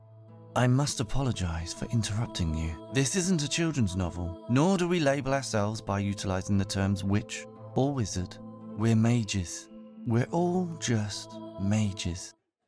Audio Book Voice Over Narrators
English (Neutral - Mid Trans Atlantic)
Yng Adult (18-29) | Adult (30-50)